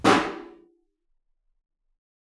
timp-snare_f.wav